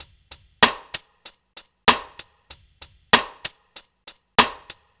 Track 14 - Drum Break 01.wav